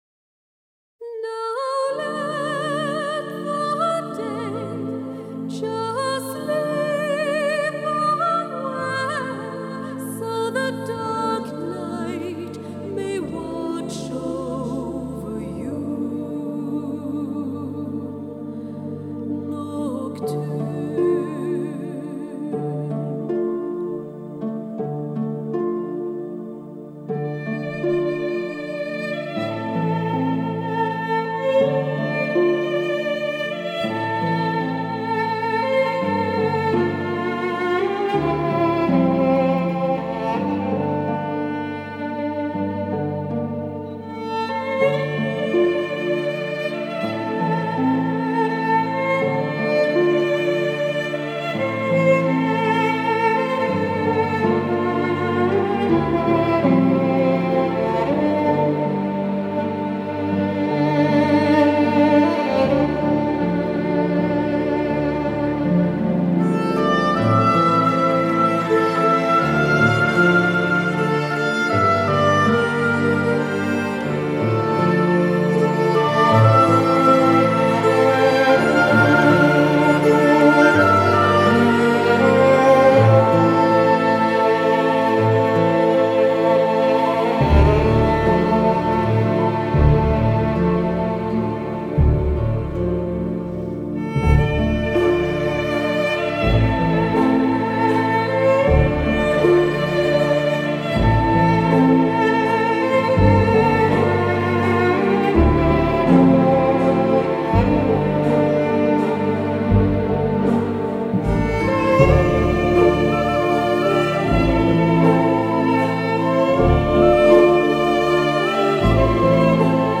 Струни вивертають душу!